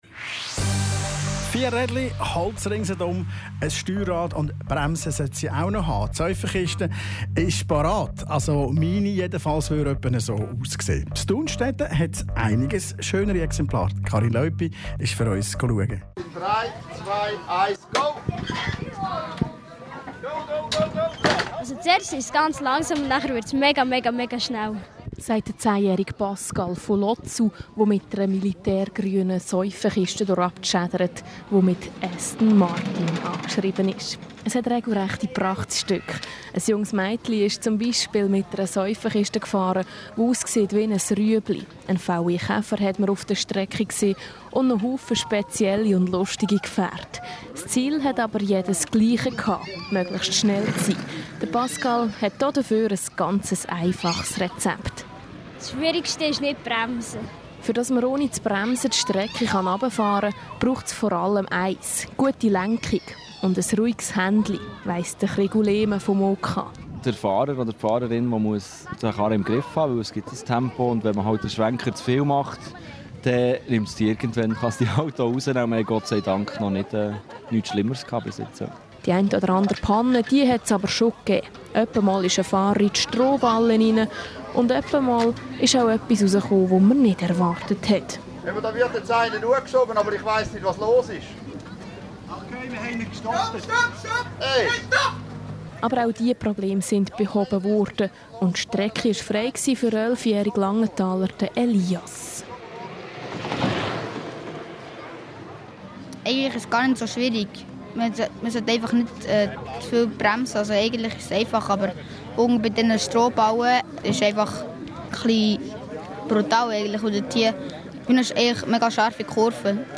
Radiobeiträge über das Seifenkistenrennen 2016, Radio 32, 20. & 21. Mai 2016